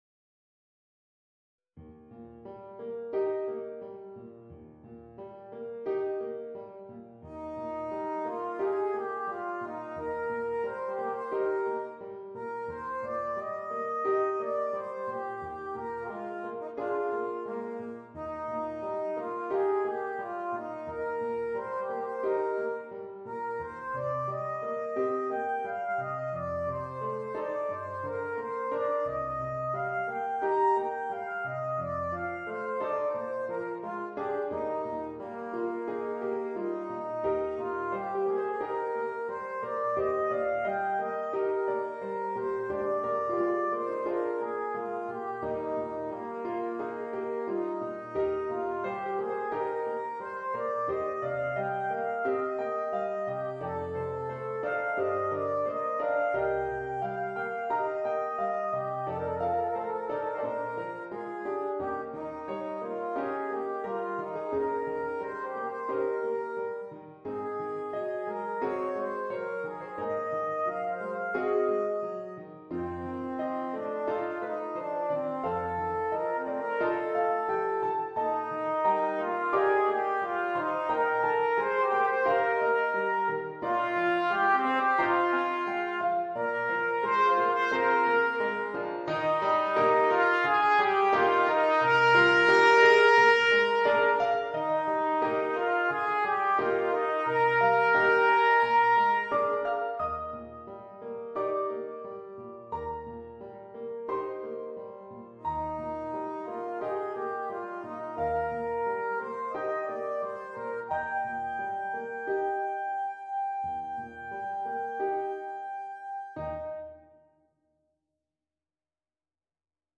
Voicing: Trumpet and Piano